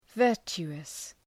Προφορά
{‘vɜ:rtʃu:əs}